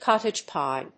アクセントcóttage píe